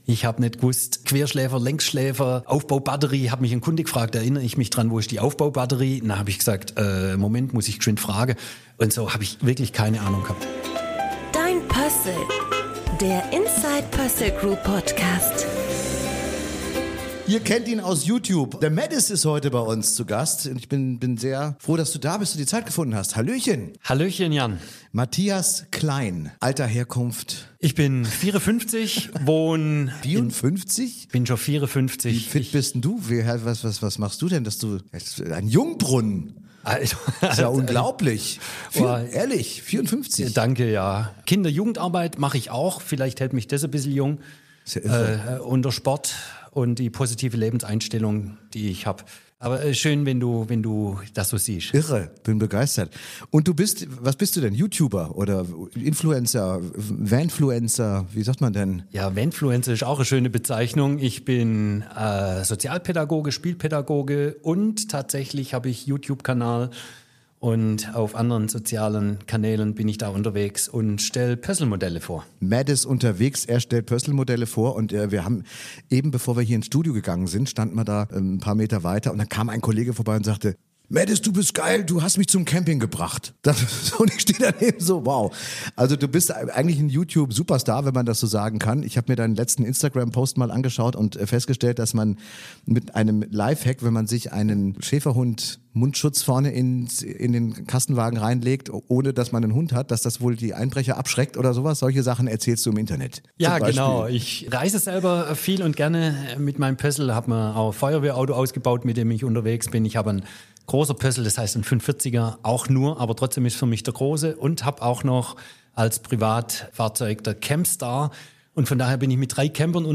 #30 MÄDES - Das Interview! ~ DEIN PÖSSL Podcast